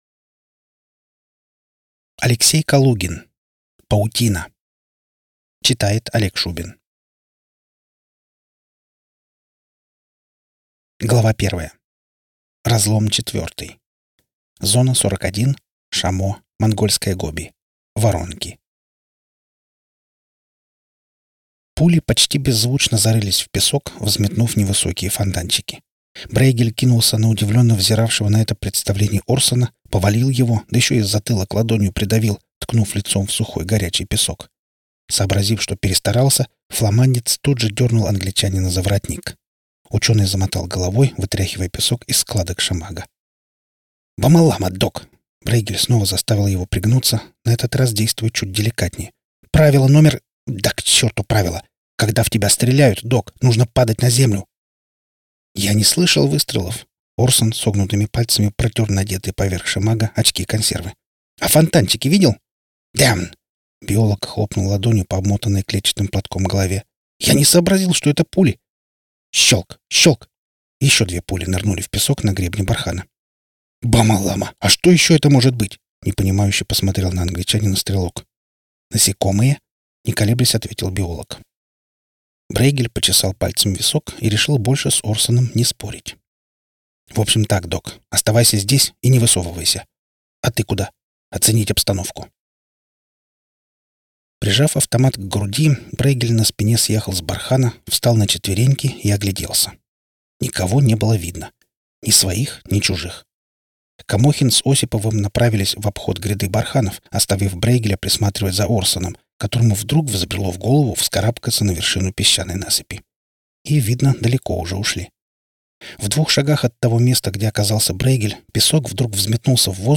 Аудиокнига Паутина | Библиотека аудиокниг